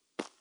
Dirt Foot Step 5.wav